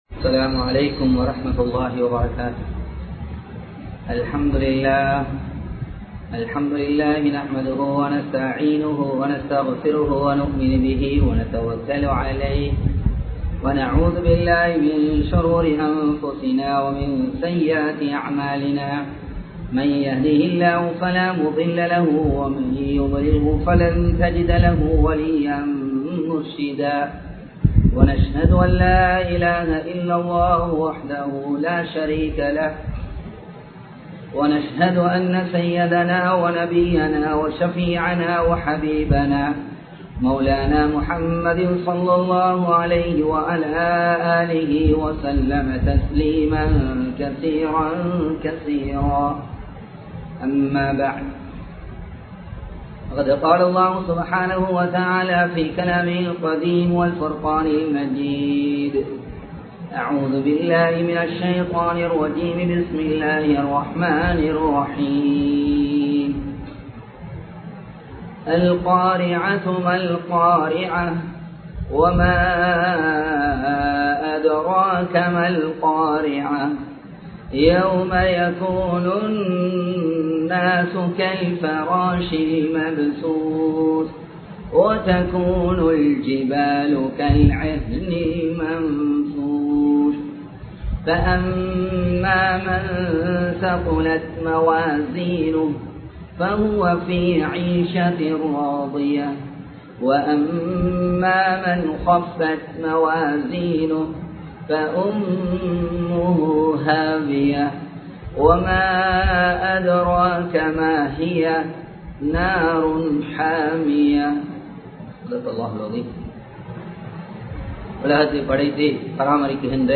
மறுமையில் பறிபோகும் நன்மைகள் (Benefits of Flipping on the Day of Resurrection) | Audio Bayans | All Ceylon Muslim Youth Community | Addalaichenai
Live Stream